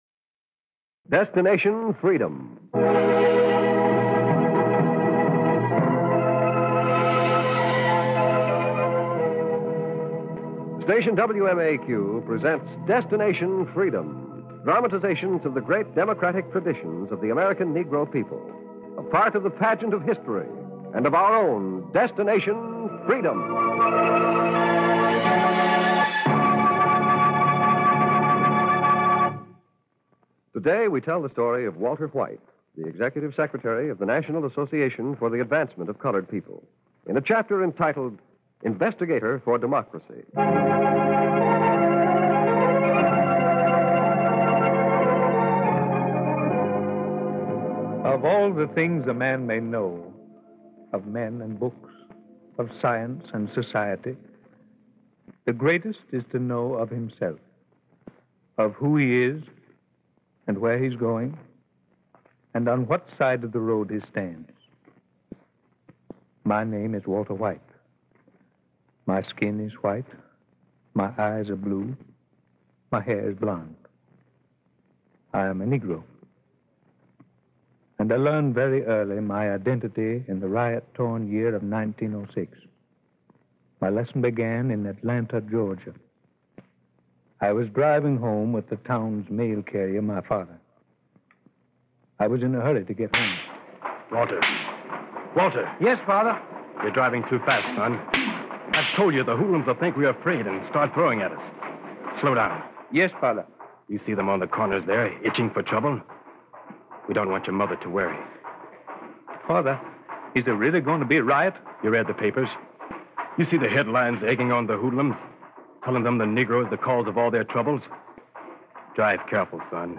"Destination Freedom" was a pioneering radio series written by Richard Durham that aired from 1948 to 1950. The series aimed to highlight the achievements and struggles of African Americans, often focusing on historical figures and events that were underrepresented in mainstream media.